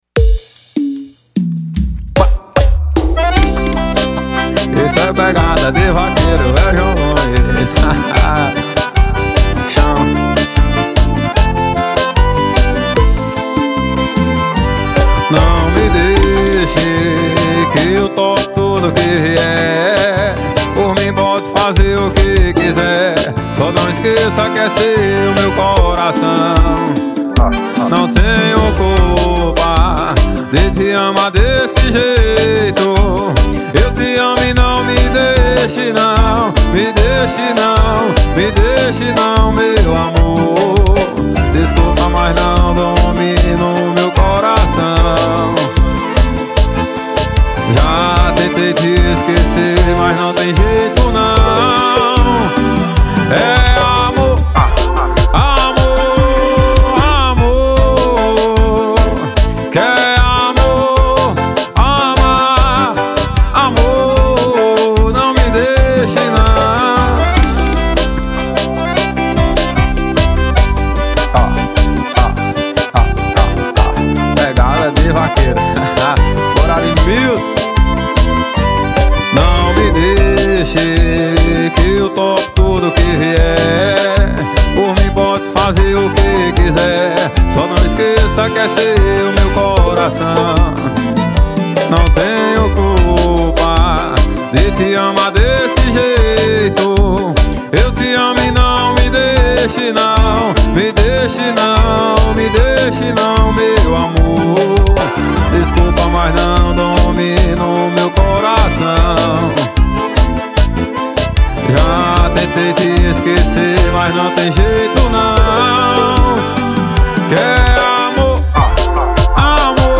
2024-02-14 17:57:24 Gênero: Forró Views